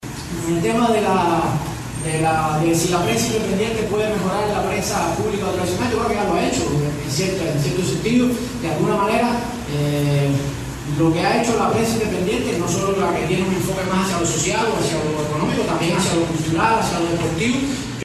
Preguntas de periodistas independientes (y respuestas) en un foro público en La Habana